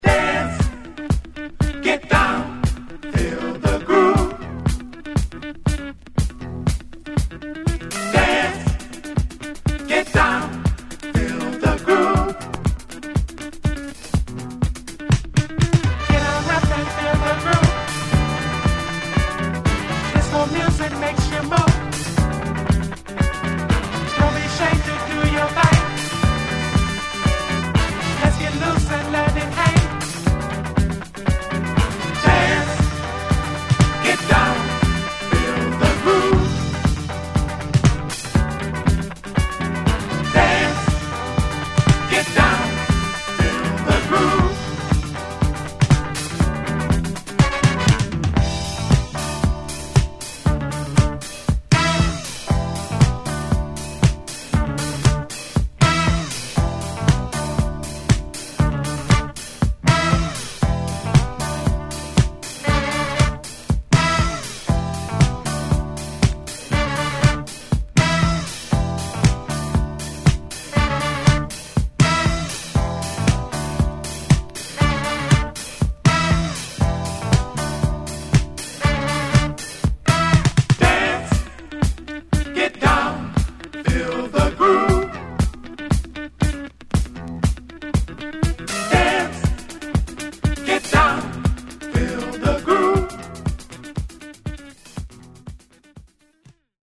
DetroitのDisco~Funk Bandによる黒いGroovy Disco！